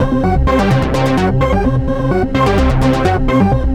AC_ArpegA_128-C.wav